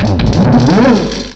sovereignx/sound/direct_sound_samples/cries/eelektross.aif at master